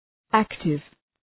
Προφορά
{‘æktıv}